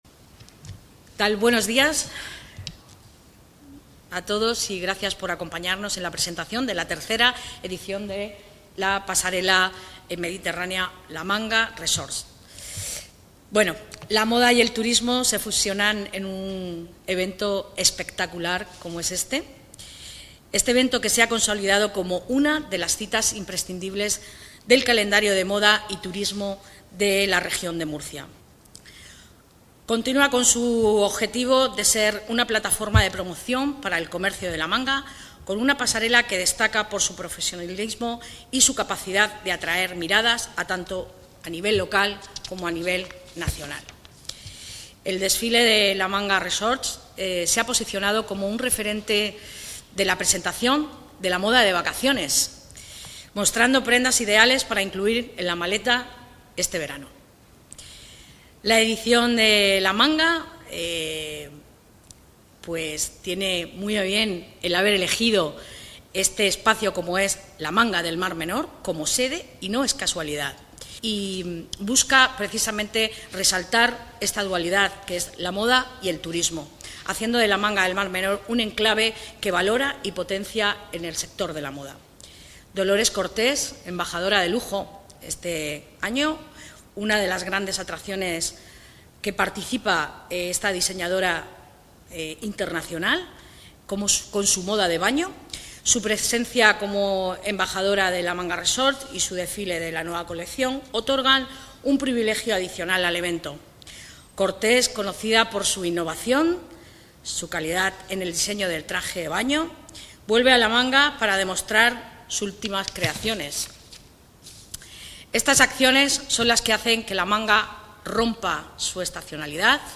El evento ha sido presentado este jueves en el Palacio Consistorial en una rueda de prensa que ha contado con la participación de la edil de Comercio, Belén Romero